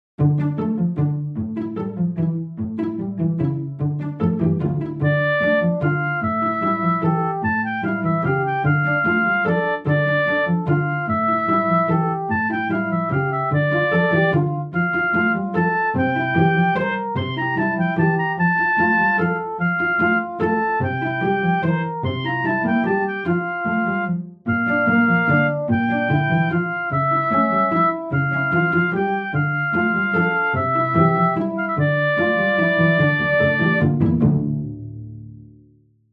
trocken (ohne Effekte)
Ouvertuere_trocken.mp3